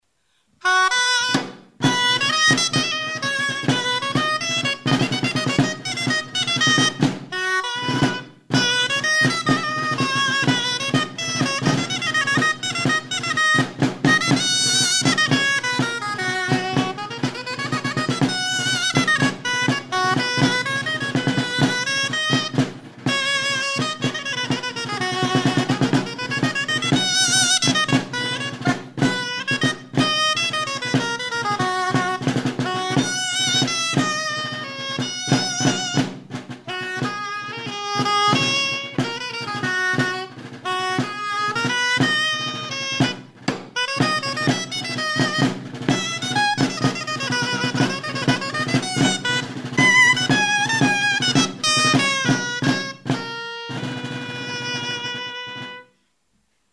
EUROPA -> KATALUNIA
GRALLA
Aerófonos -> Lengüetas -> Doble (oboe)